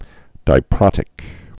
(dī-prŏtĭk)